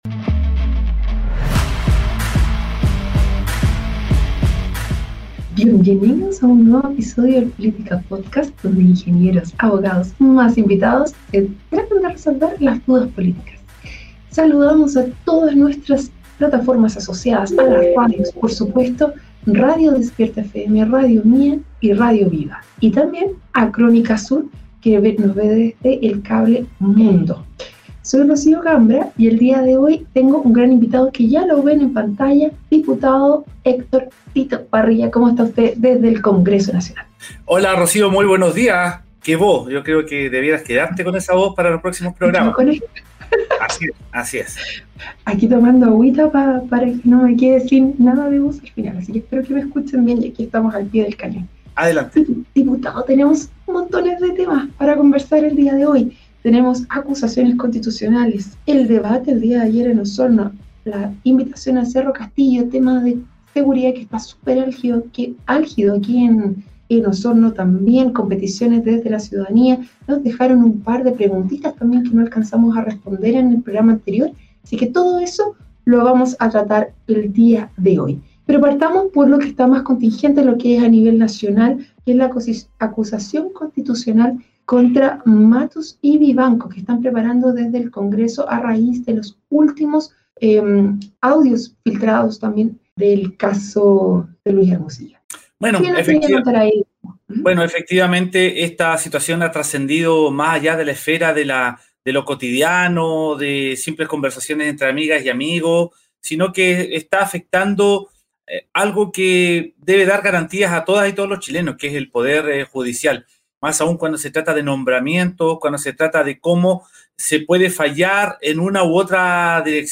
En una reciente aparición en el Política Podcast, el diputado Héctor Barría abordó temas relevantes para la contingencia nacional y regional, destacando la crisis que enfrenta el Poder Judicial y la seguridad pública en Osorno.
Durante la entrevista, se discutieron temas como las acusaciones constitucionales, el tráfico de influencias y las necesidades urgentes en materia de seguridad.